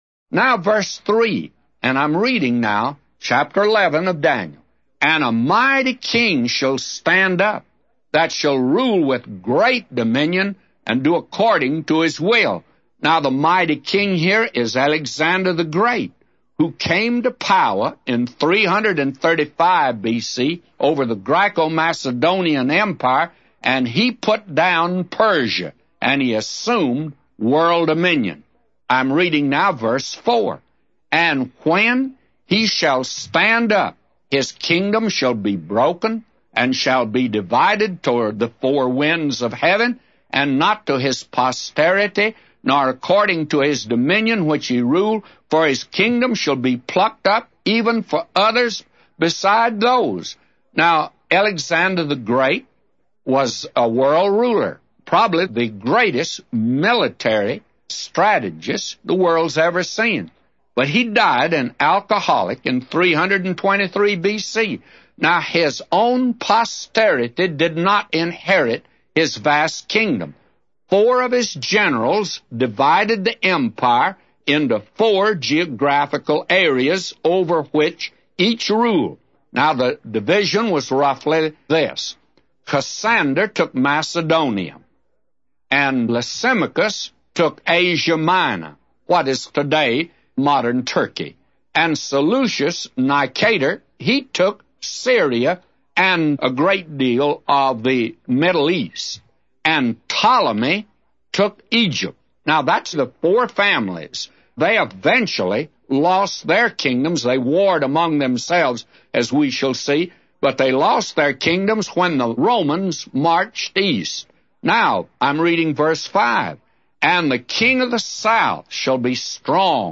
A Commentary By J Vernon MCgee For Daniel 11:3-999